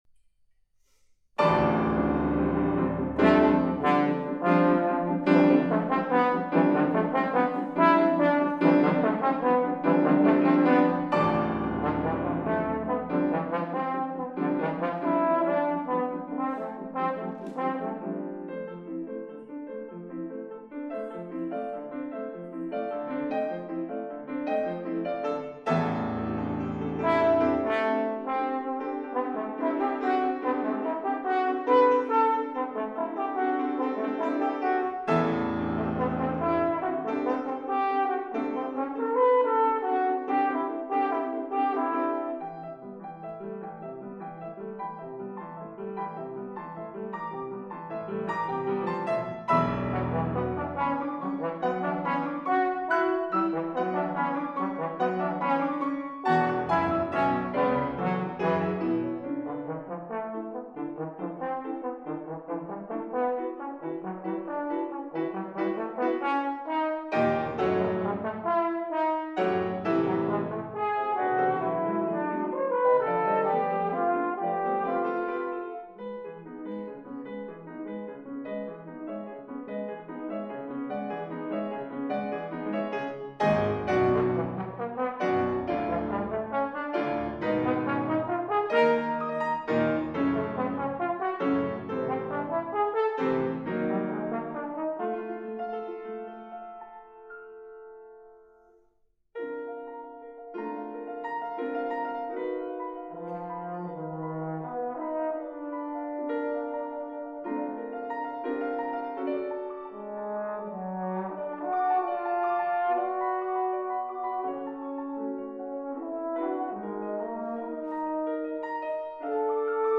for Trombone and Piano
trombone
piano